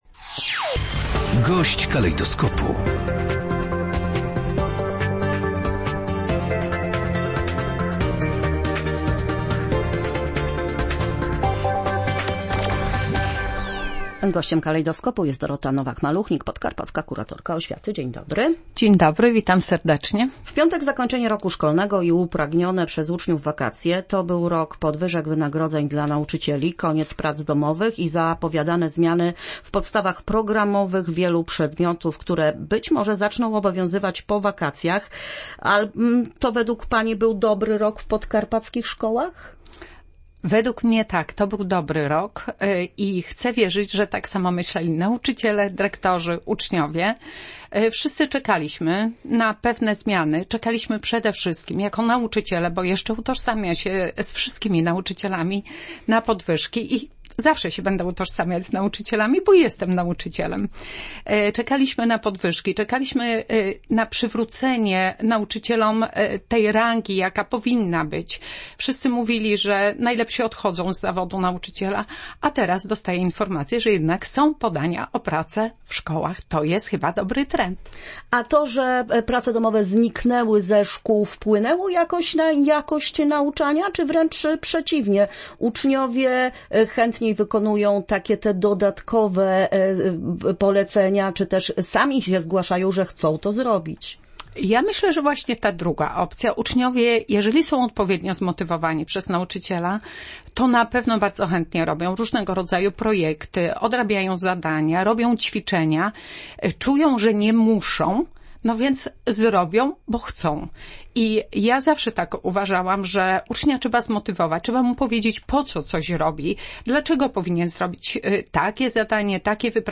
– oceniła na naszej antenie podkarpacka kurator oświaty Dorota Nowak-Maluchnik.